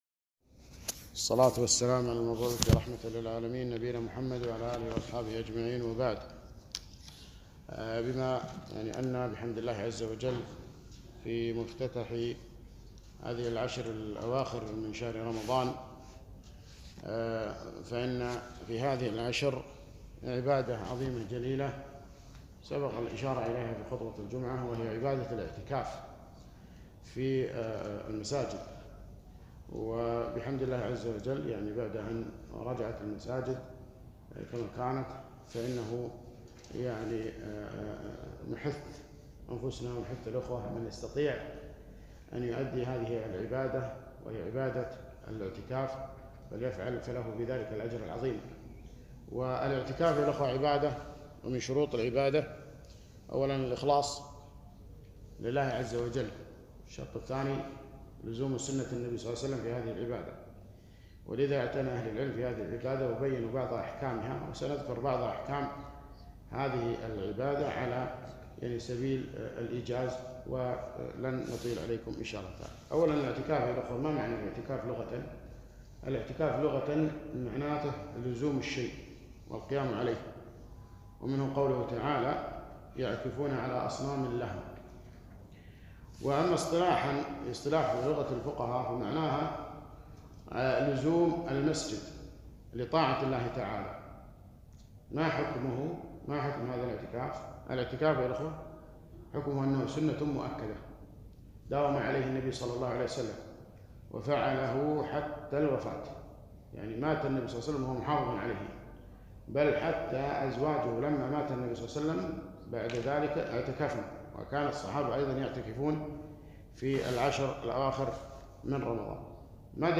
محاضرة - الاعتكاف أحكام وآداب